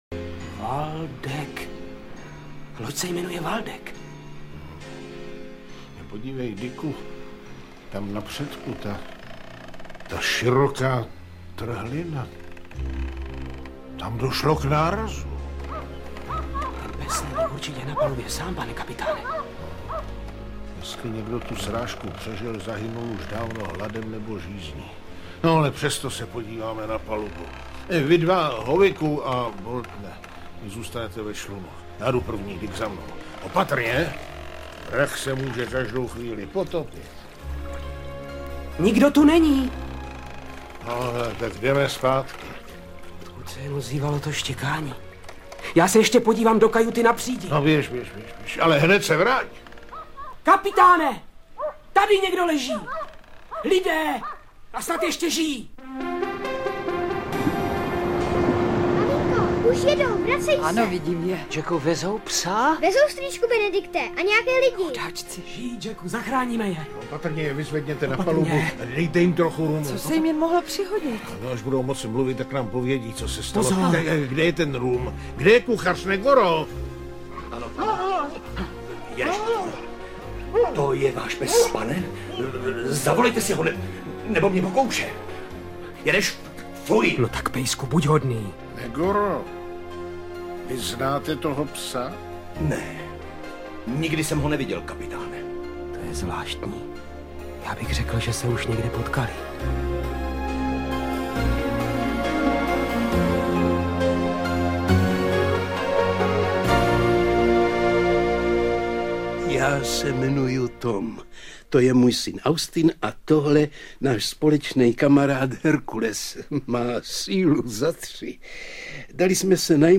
Patnáctiletý kapitán audiokniha
Ukázka z knihy
Před 22 lety, v roce 1991 jsme si pro svoje potěšení natočili v Jevanech ve studiu pana Štaidla dramatizaci Verneovky.
Po prvním, poněkud nevydařeném vydání jsme vzali tento archivní záznam opět do rukou a oprášili ho.